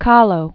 (kälō), Frida 1907-1954.